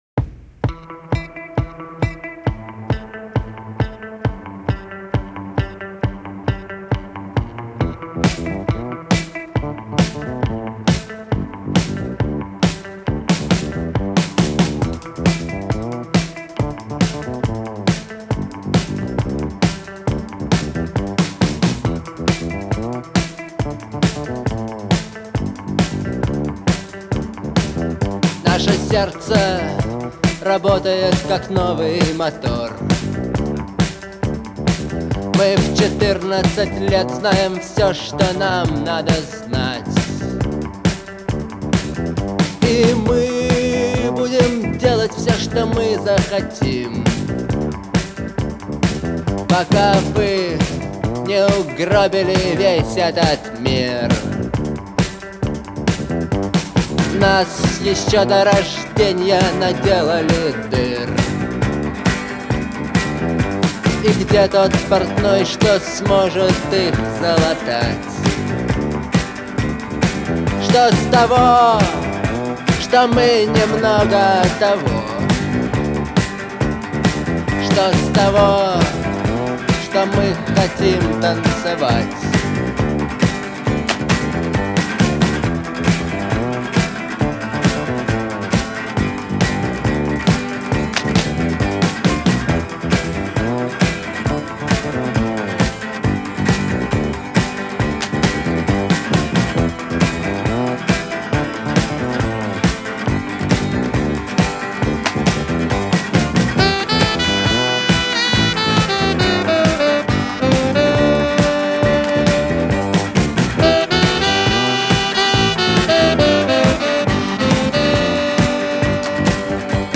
энергичная и ритмичная песня